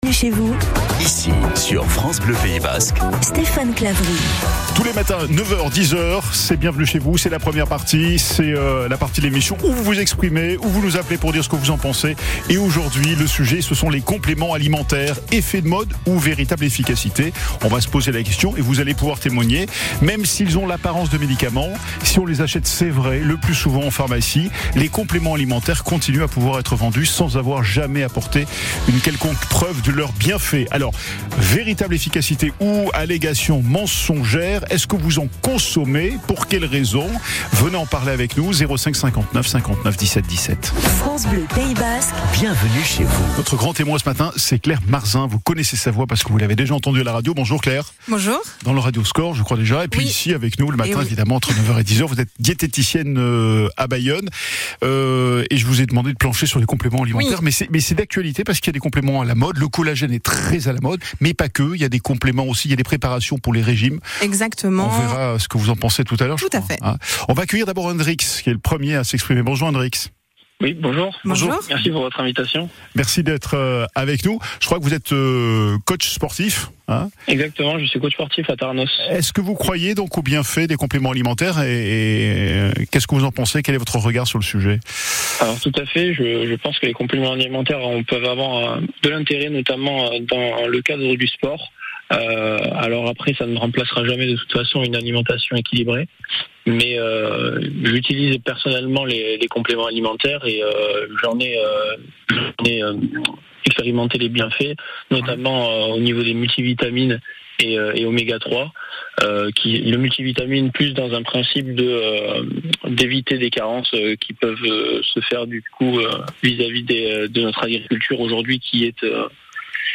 A l’automne dernier, je suis passée à France Bleu Pays basque sur le thème des compléments alimentaires.